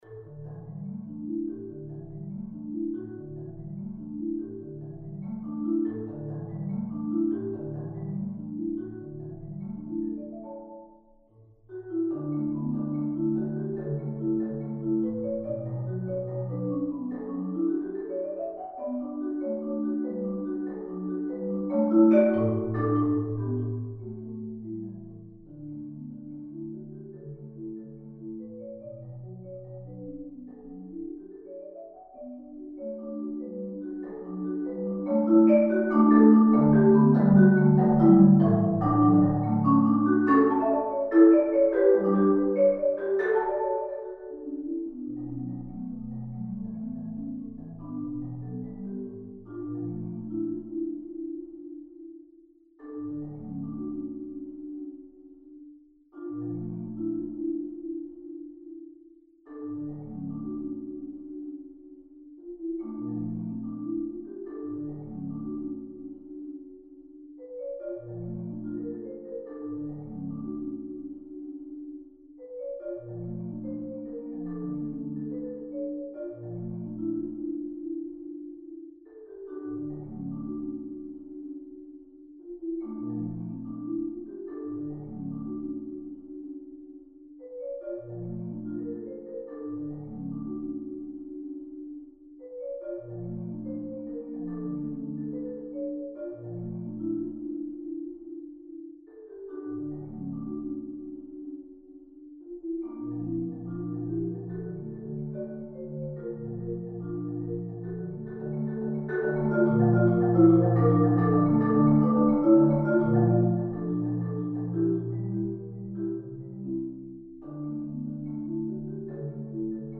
Voicing: 4 Mallet Marimba Solo